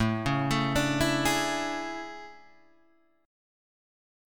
AM11 Chord
Listen to AM11 strummed